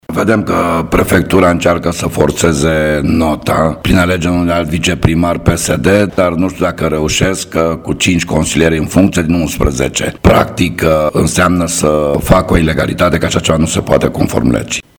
Într-o conferință de presă, desfășurată la sediul PNL Brașov, a fost prezentată situația administrativă incredibilă din conuna brașoveană Augostin.
Decizia consilierilor locali PNL din comuna Augustin ne-a fost explicată de Tokos Joszef (foto), fost consilier local PNL, de două ori primar al localității: